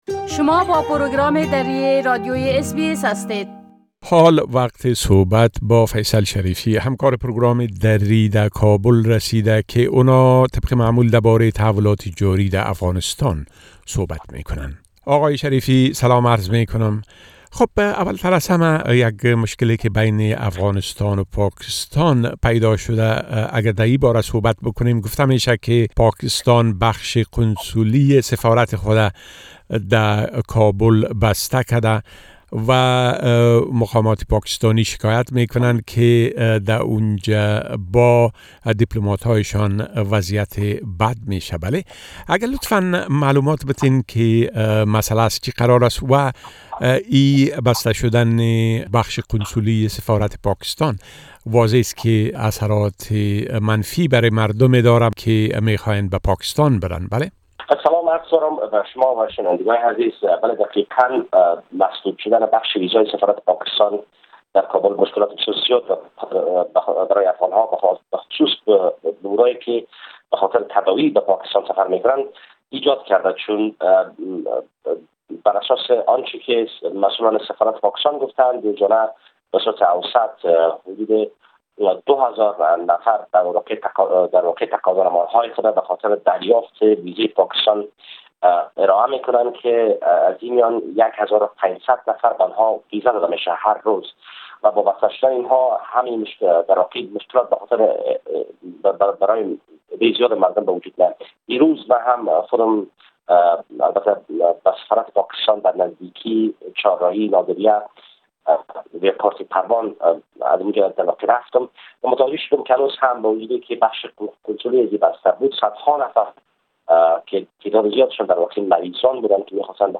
گرازش كامل خبرنگار ما در كابل به شمول تازه ترين ها در ارتباط به انتخابات رياست جمهورى و رويداد هاى مهم ديگر در افغانستان را در اينجا شنيده ميتوانيد.